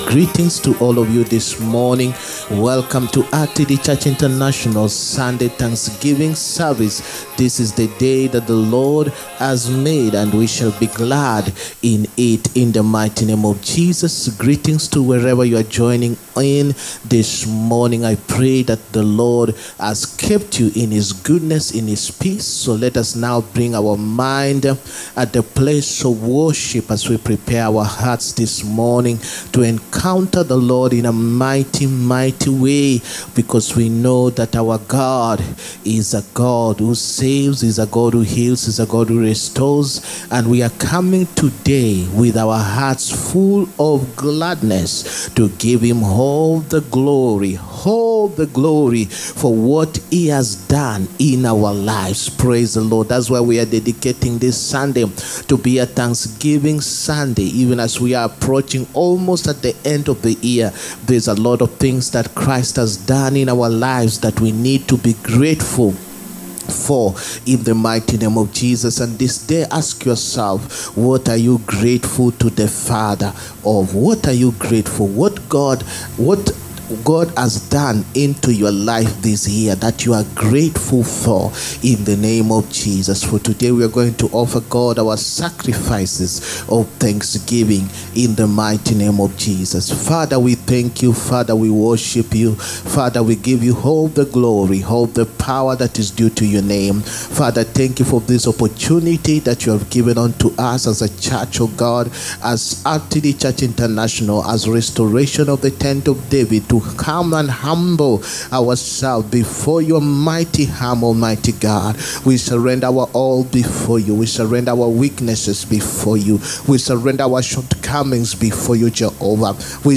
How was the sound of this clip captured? SUNDAY SERVICE. THEME: THE POWER OF BLESSINGS. 17TH NOVEMBER 2024. SUNDAY-SERVICE.-THE-POWER-OF-BLESSINGS.mp3